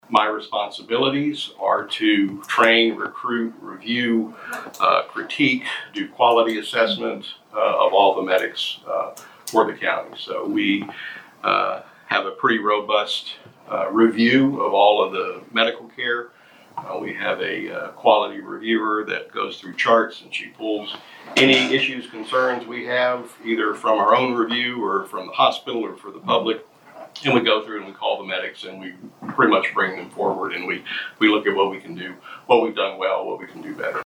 In Walker County Commissioners’ Court Monday